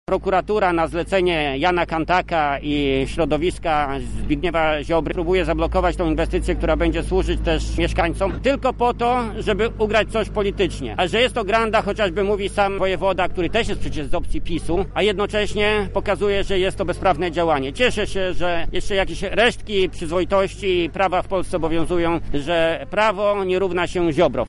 Mamy do czynienia z wielką grandą ponieważ politycy zawłaszczają sobie organy państwa– mówi senator Jacek Bury: